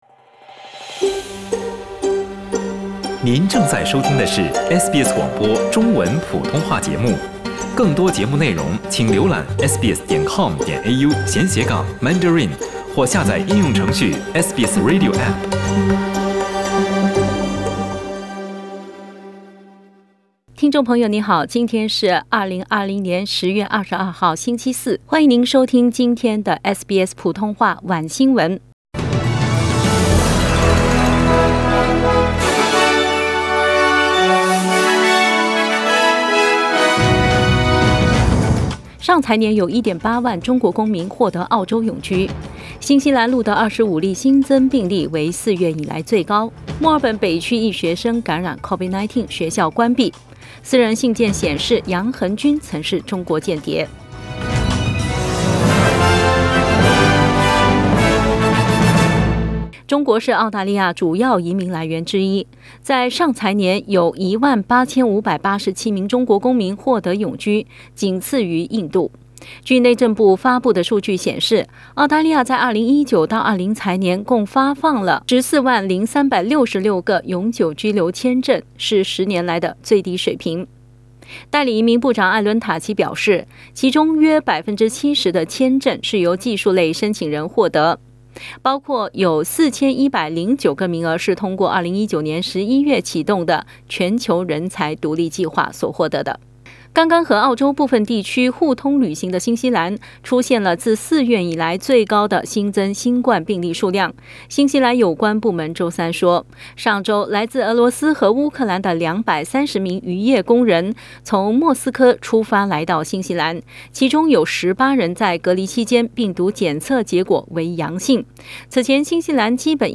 SBS晚新闻（10月22日）